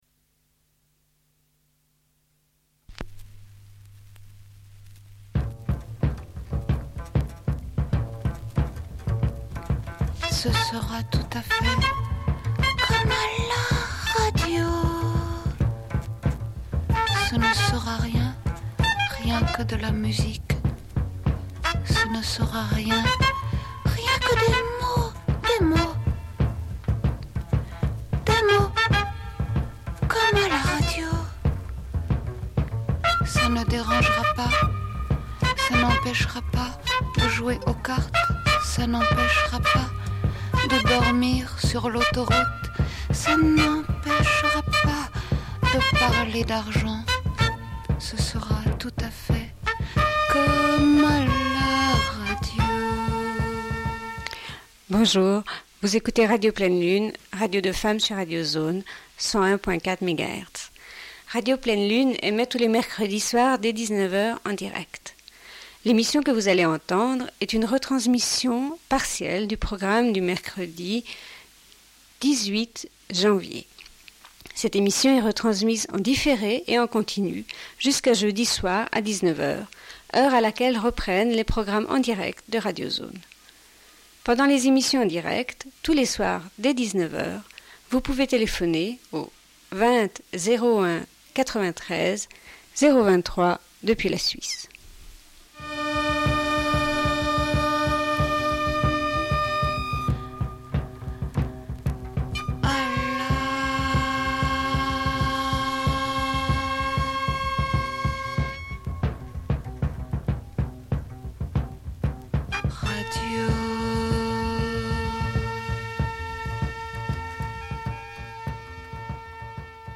Une cassette audio, face A47:10